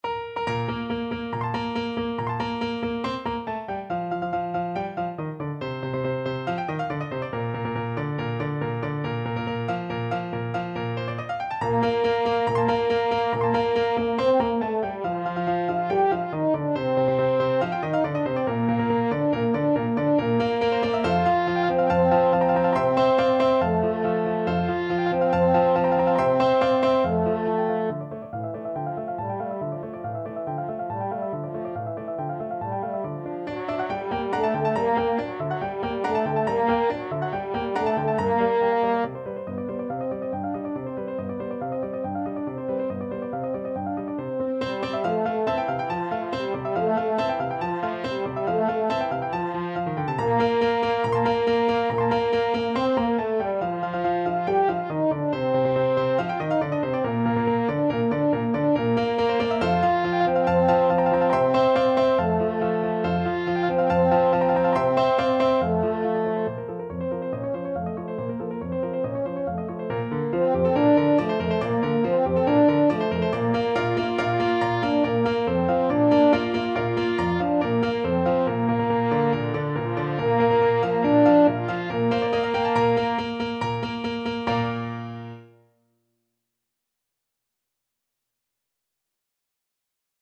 French Horn
Bb major (Sounding Pitch) F major (French Horn in F) (View more Bb major Music for French Horn )
2/4 (View more 2/4 Music)
~ = 140 Allegro vivace (View more music marked Allegro)
Classical (View more Classical French Horn Music)